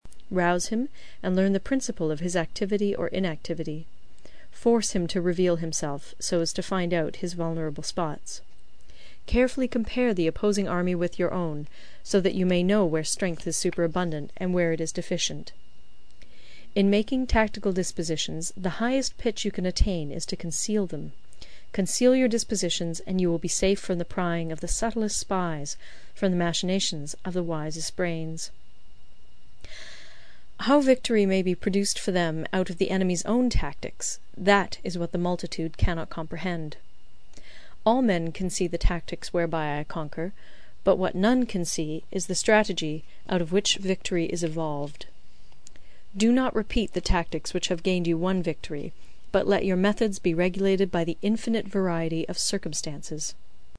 有声读物《孙子兵法》第37期:第六章 虚实(6) 听力文件下载—在线英语听力室